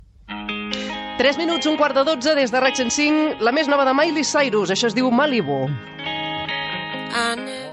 Hora, identificació de l'emissora i presentació d'un tema musical
FM
Recreació feta al programa "Islàndia" de RAC 1 emès el 18 de febrer de l'any 2018